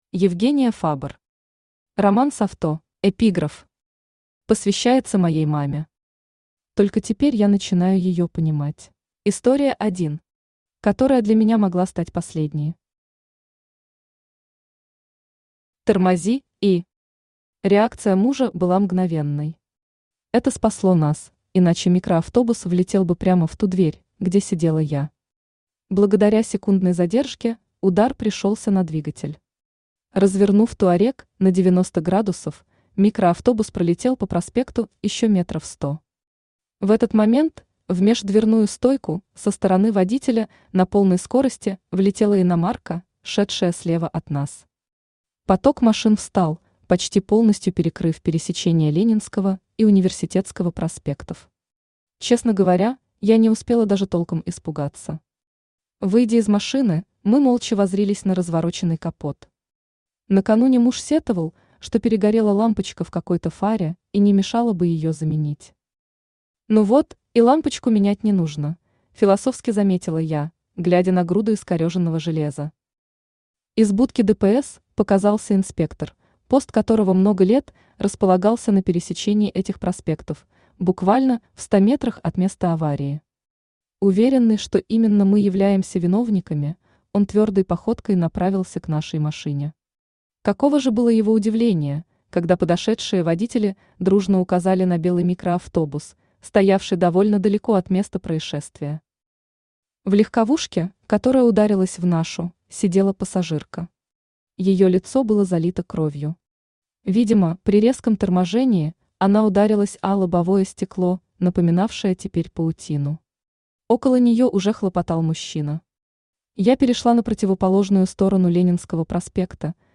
Аудиокнига Роман с АВТО | Библиотека аудиокниг
Aудиокнига Роман с АВТО Автор Евгения Фабр Читает аудиокнигу Авточтец ЛитРес.